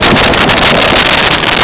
Sfx Crash Metal Med2
sfx_crash_metal_med2.wav